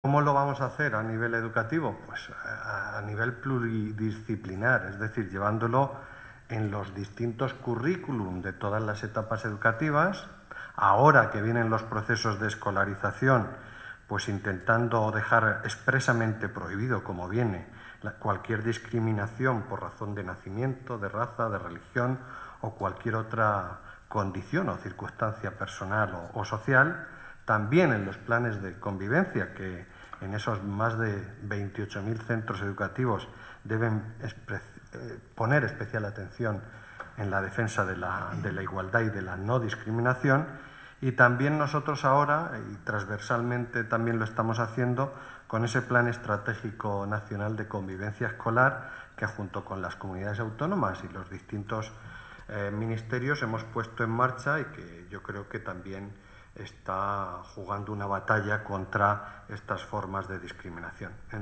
Palabras de Marcial Marín AudioSalto de línea